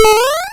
M1_SmallMarioJump.wav